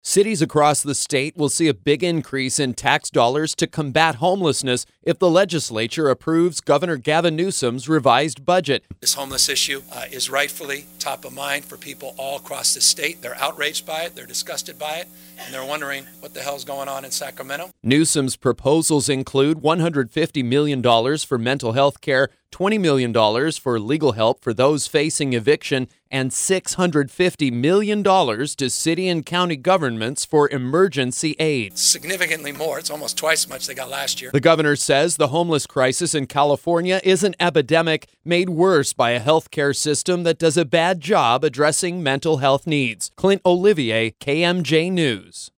Gov. Gavin Newsom discusses his revised 2019-20 state budget during a news conference Thursday in Sacramento.